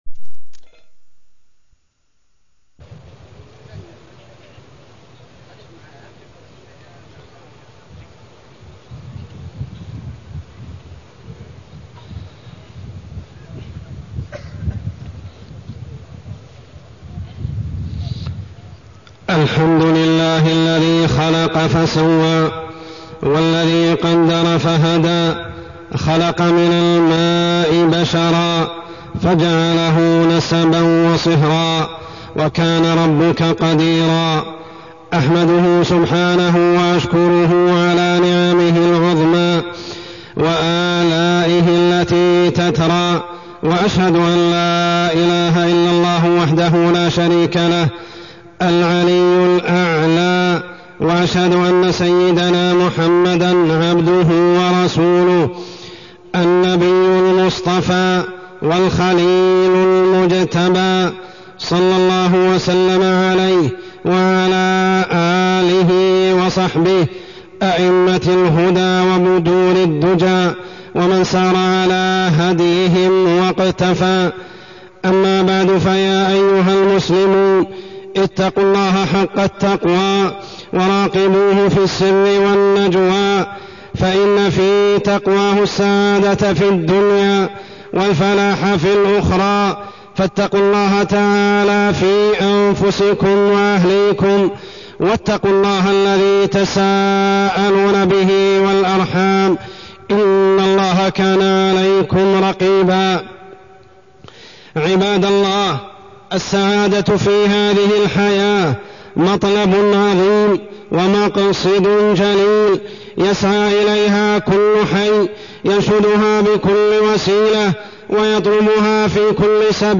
تاريخ النشر ٢٣ ربيع الأول ١٤٢٢ هـ المكان: المسجد الحرام الشيخ: عمر السبيل عمر السبيل الحث على الزواج